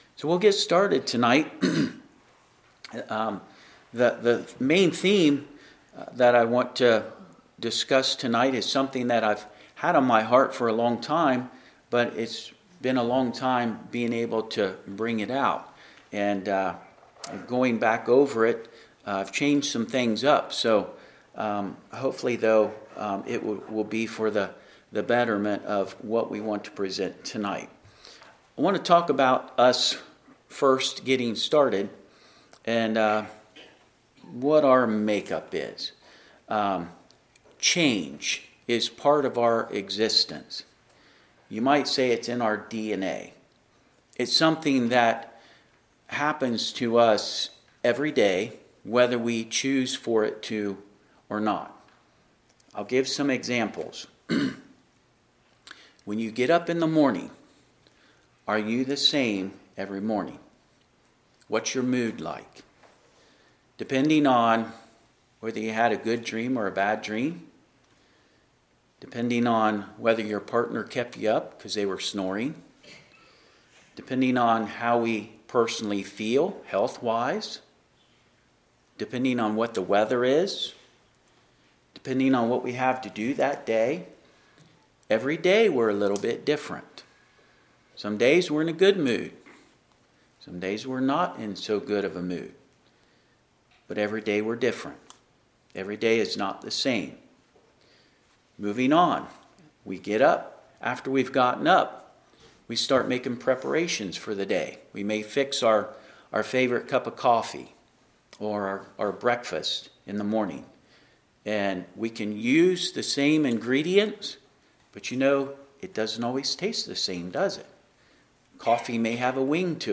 Wednesday Sermon